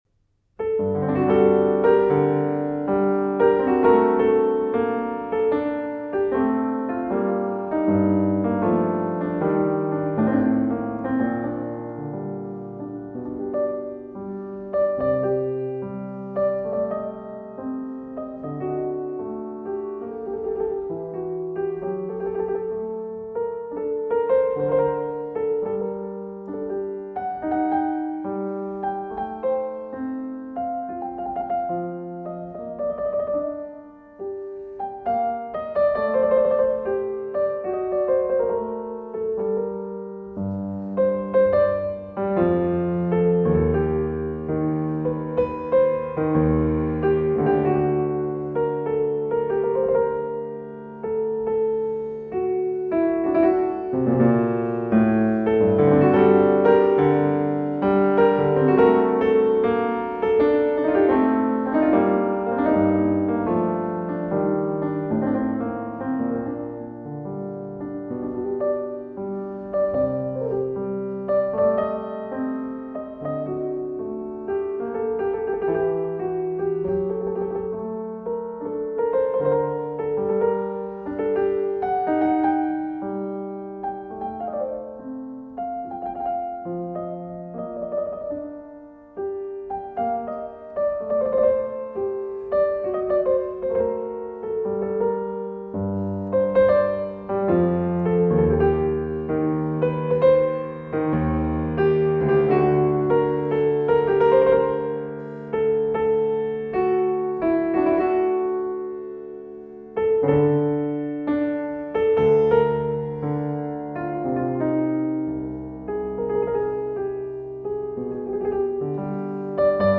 keyboard suites on piano